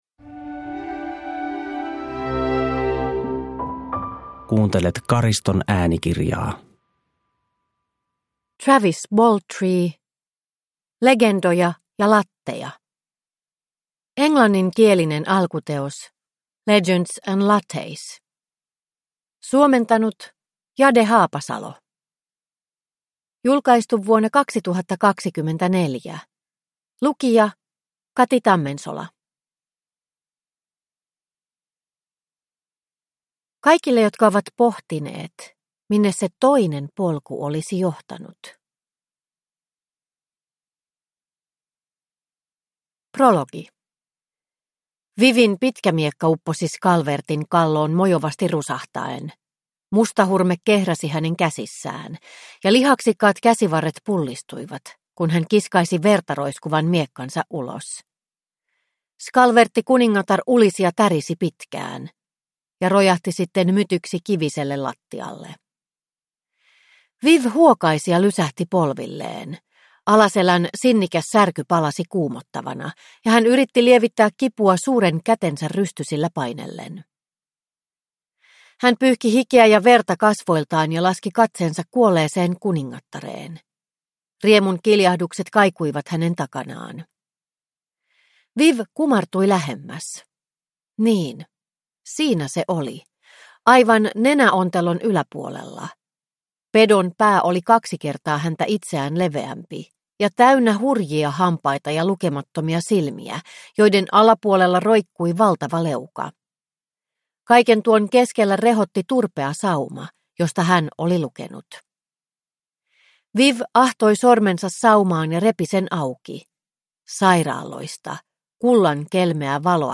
Legendoja ja latteja – Ljudbok